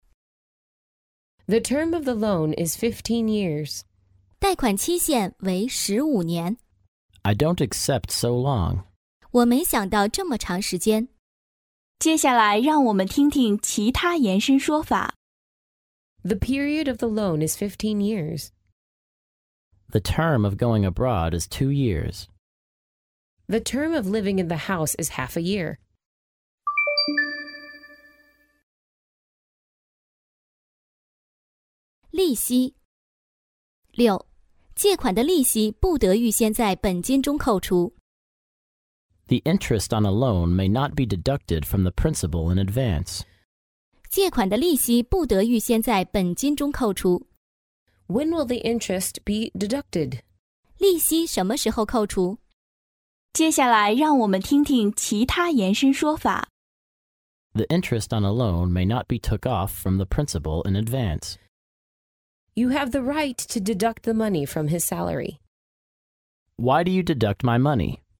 真人发音的朗读版帮助网友熟读熟记，在工作中举一反三，游刃有余。